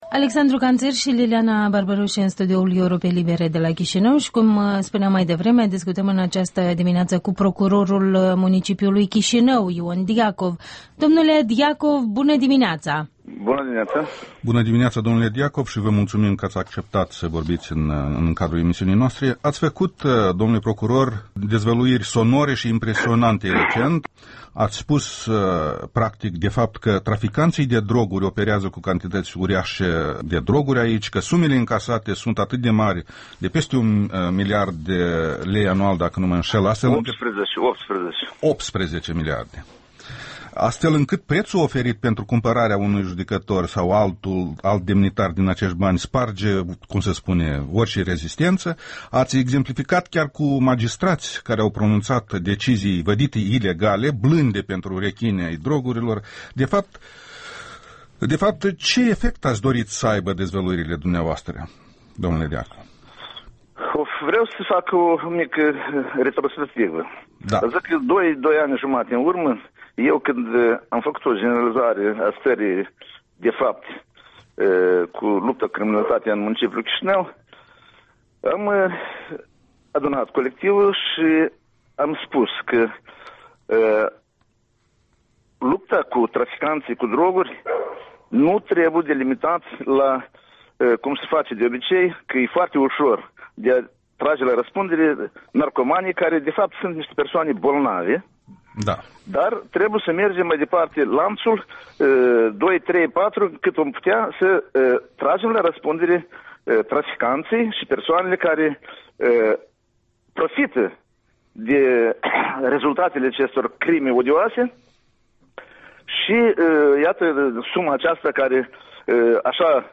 Interviul dimineții cu Ion Diacov, procurorul municipiului Chișinău